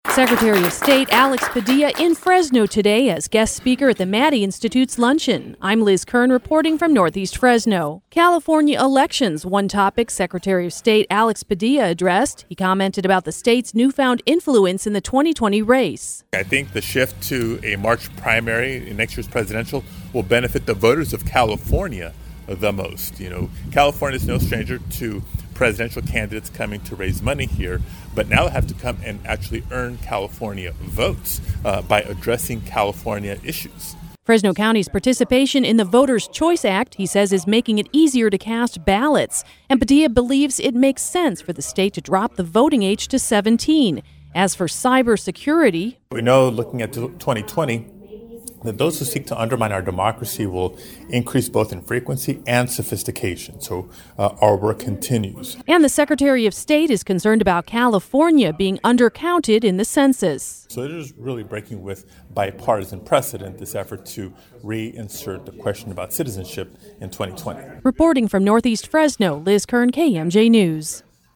FRESNO, CA (KMJ) – California Secretary of State Alex Padilla visited Fresno as a guest of the Maddy Institute’s Associates Luncheon.
As the guest speaker at the event held Tuesday afternoon at Vino Grille & Spirits in Northeast Fresno, Secretary Padilla was speaking about two main topics; California Elections and Census Participation. Padilla calls it great news that in 2020 there will be more options as to when, where, and how people will cast their vote as Fresno is one of the five counties participating in the Voter’s Choice Act.